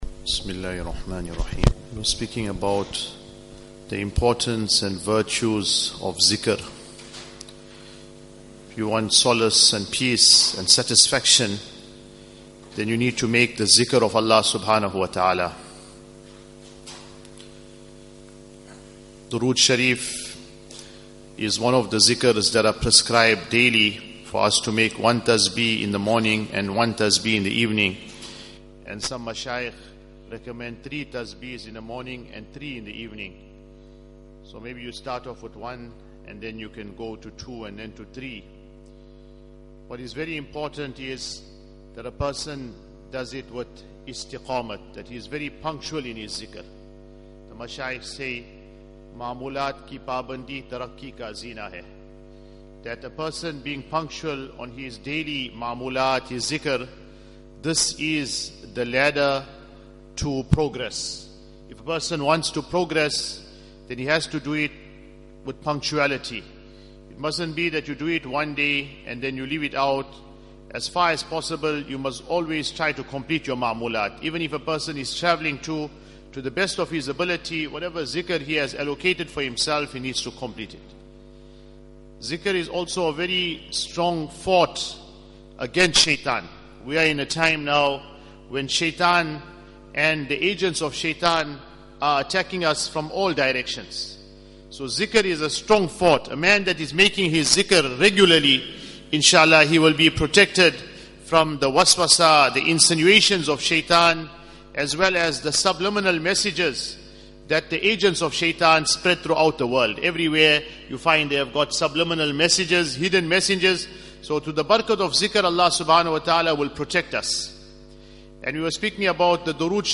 Morning Discourses